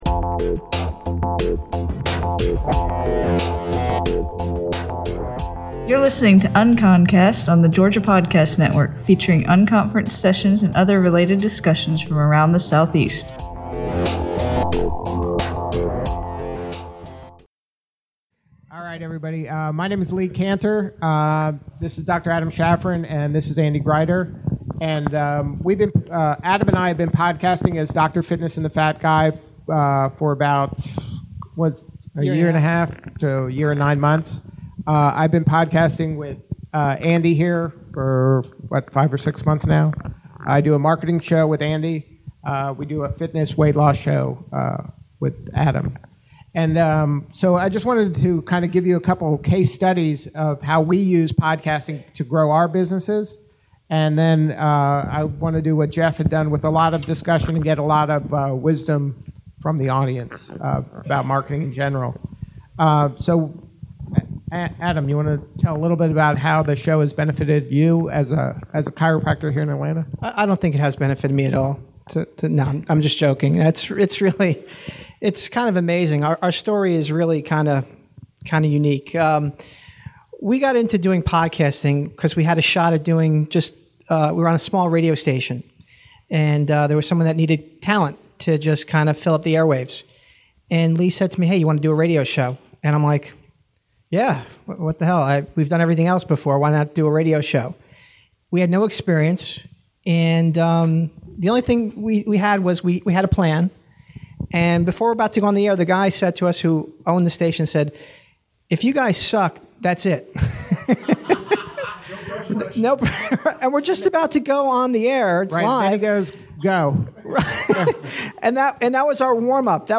Featuring unconference sessions and other related discussions from around the Southeast.